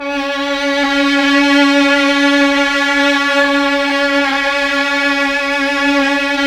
Index of /90_sSampleCDs/Keyboards of The 60's and 70's - CD1/KEY_Chamberlin/STR_Chambrln Str
STR_Chb StrC#5-L.wav